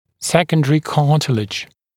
[‘sekəndərɪ ‘kɑːtɪlɪʤ][‘сэкэндэри ‘ка:тилидж]вторичный хрящ